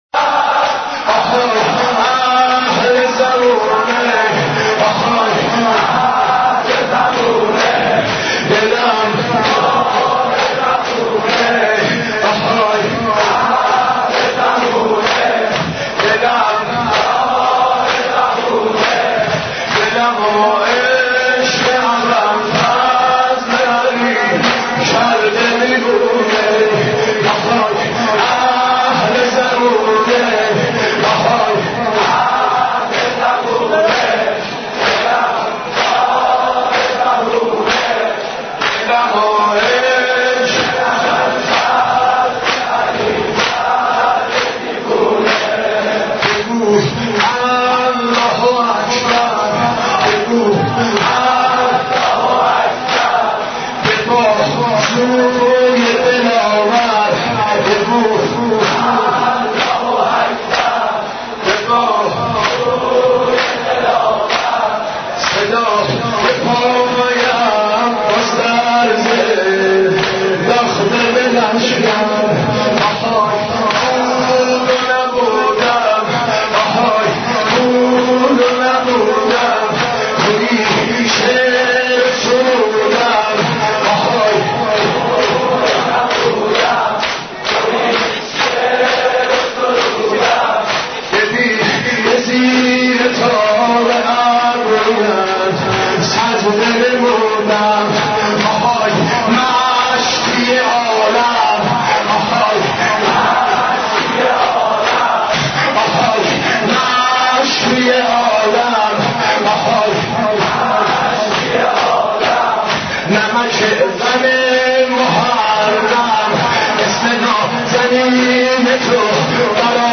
حضرت عباس ع ـ شور 12